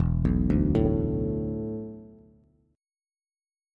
E7sus4 Chord